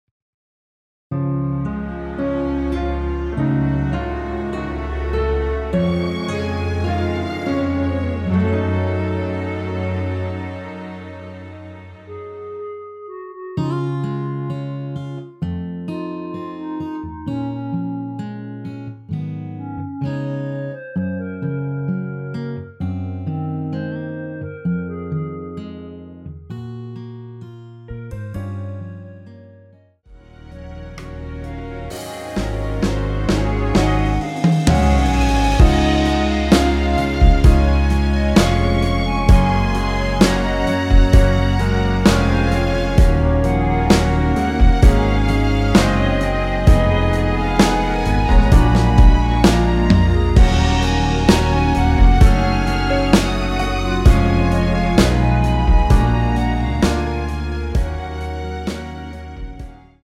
원키에서(-1)내린 멜로디 포함된 MR입니다.(미리듣기 확인)
Db
앞부분30초, 뒷부분30초씩 편집해서 올려 드리고 있습니다.
중간에 음이 끈어지고 다시 나오는 이유는